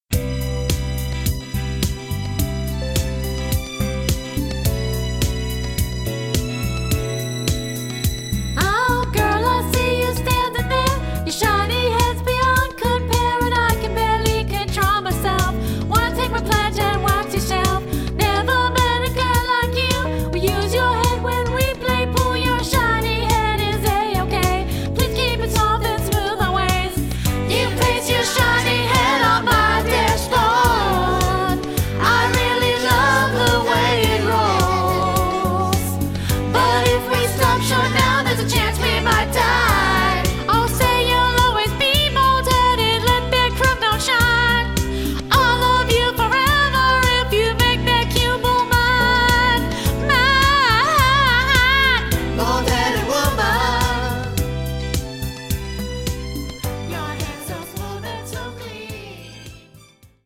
(CD - $12.95)--hilarious song parodies